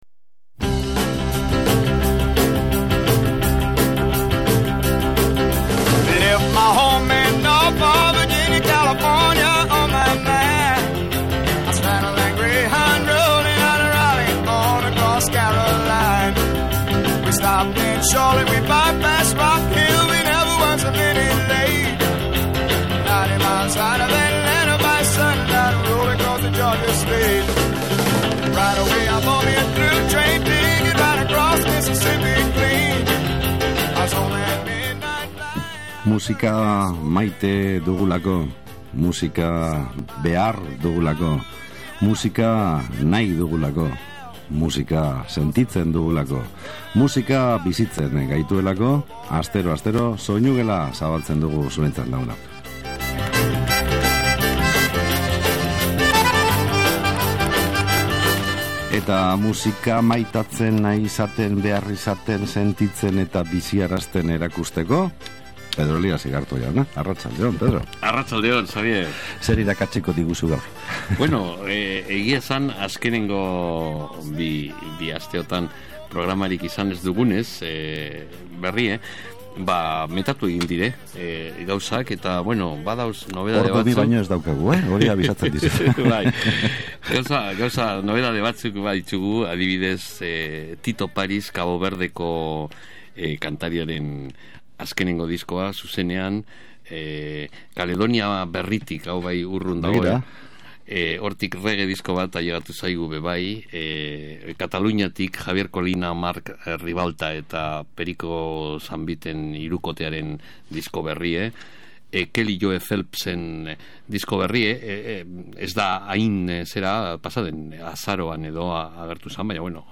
bi kantu malenkoniatsu entzuteko
soleá bat eskaini digute jarraian
Kubako musikan murgildu gara